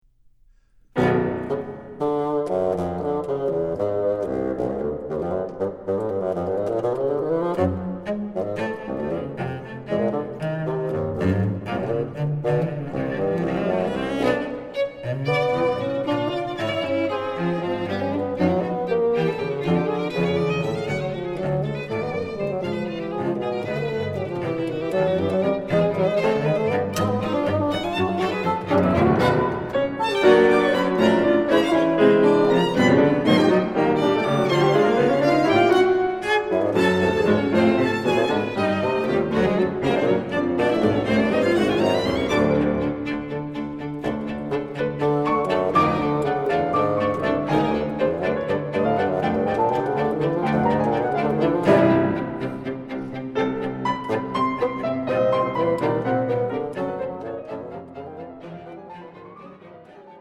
Quartet for Bassoon, Violin, Cello and Piano